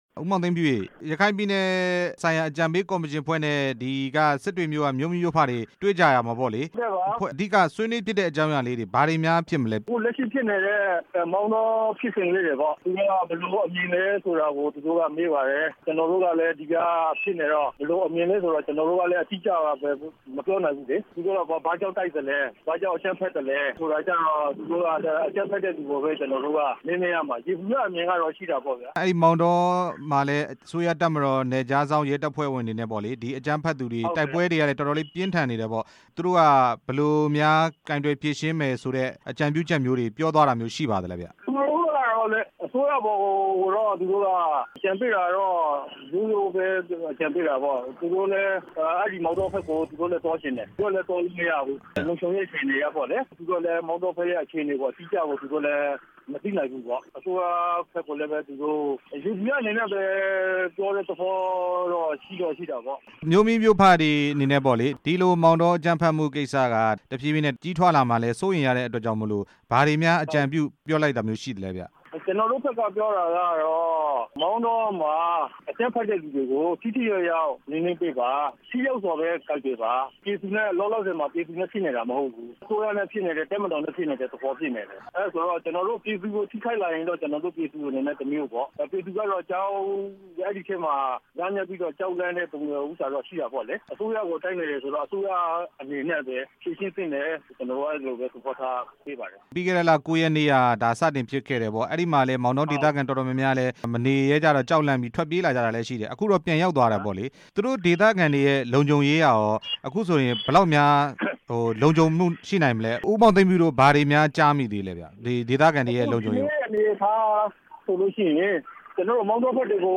ရခိုင်အကြံပေးကော်မရှင်နဲ့ တွေ့ဆုံခဲ့တဲ့ မြို့မိမြို့ဖတစ်ဦး ကို မေးမြန်းချက်